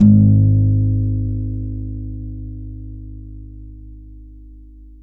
52-str03-abass-g#1.aif